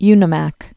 (ynə-măk)